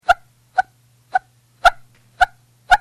砂の発音特性をしらべてみた。
送られてきた砂そのままの音スペクトル